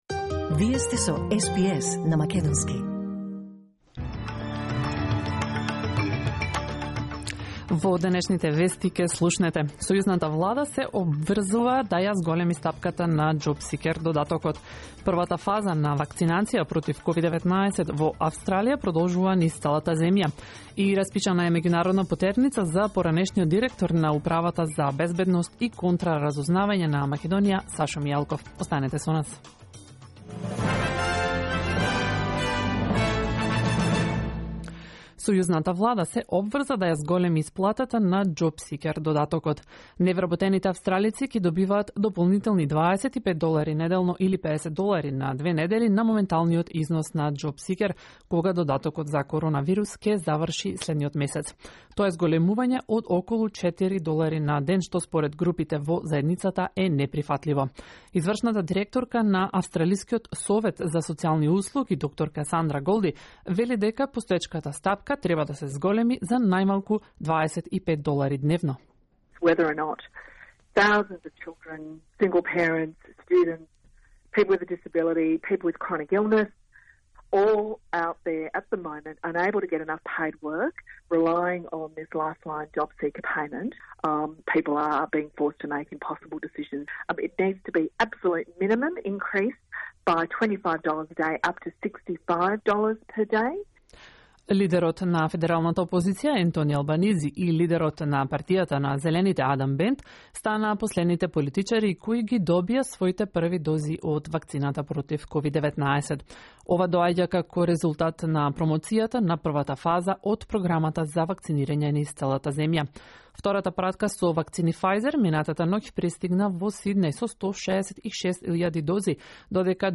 SBS News in Macedonian 23 February 2021